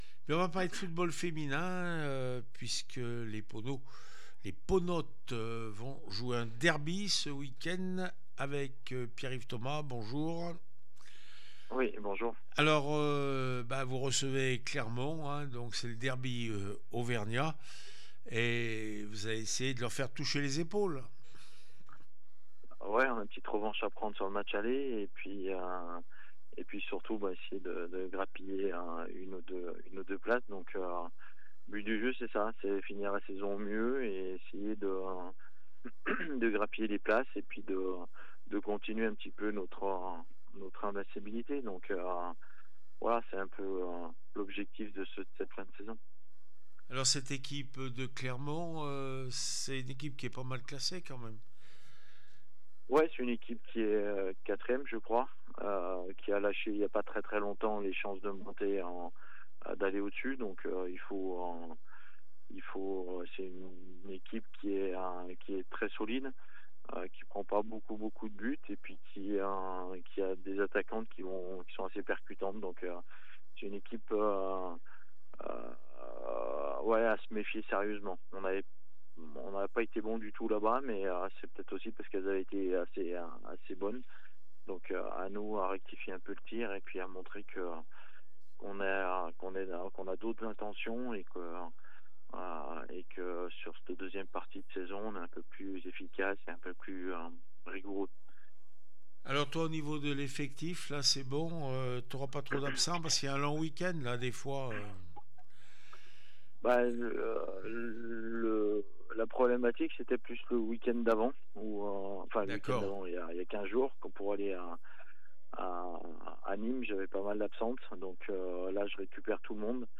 9 mai 2025   1 - Sport, 1 - Vos interviews